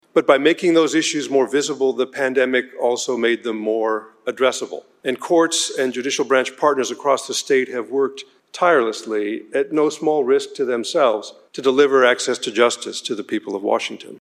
WA Supreme Court Chief Justice González Delivers State of the Judiciary Address (Listen/Watch)
OLYMPIA, WA – The Washington State Senate and House of Representatives gathered for a joint session today to hear State Supreme Court Chief Justice Steven González deliver the State of the Judiciary Address.